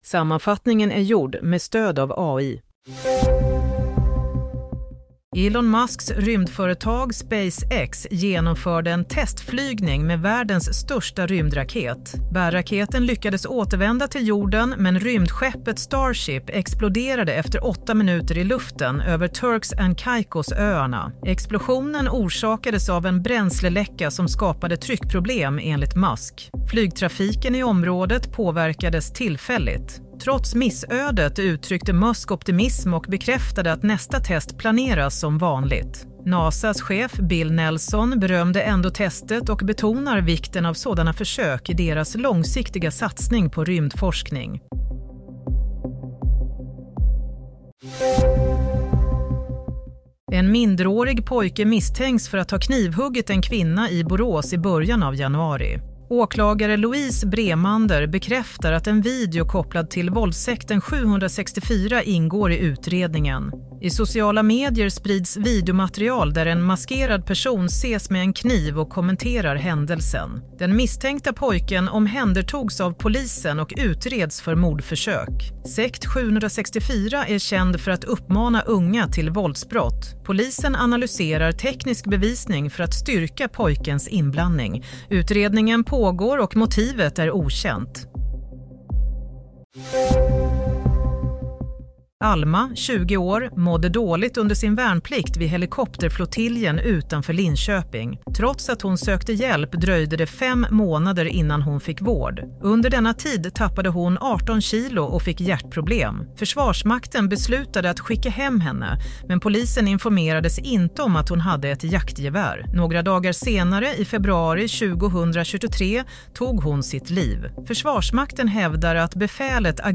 Nyhetssammanfattning - 17 januari 07:00
Sammanfattningen av följande nyheter är gjord med stöd av AI.